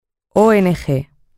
Escucha como se pronuncian las siguientes siglas